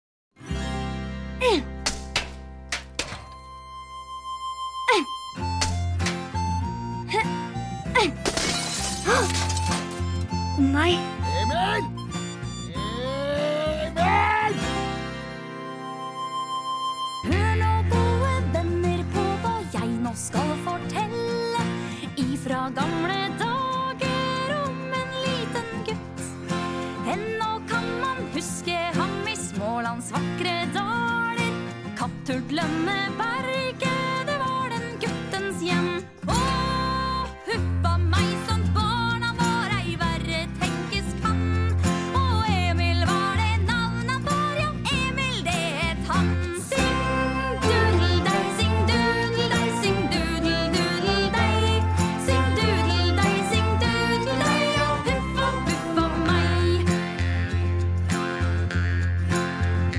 Da Emil satt fast i suppebollen (lydbok) av Astrid Lindgren